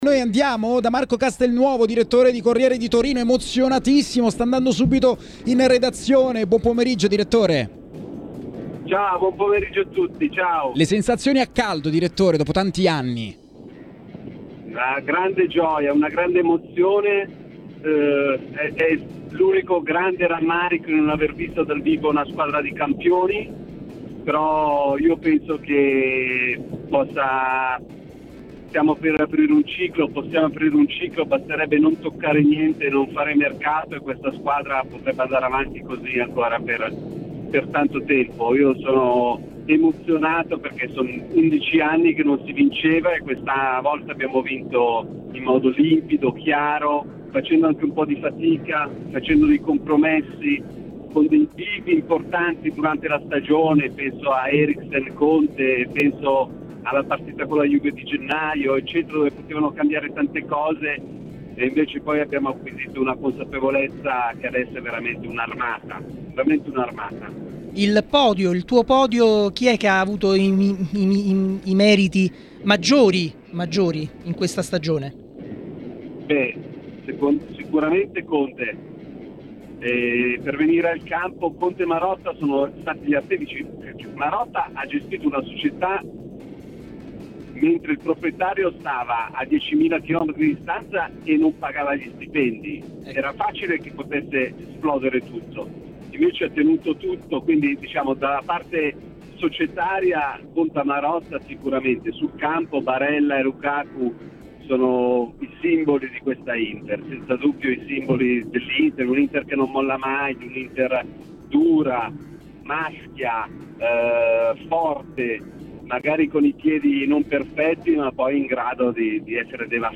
TMW Radio Regia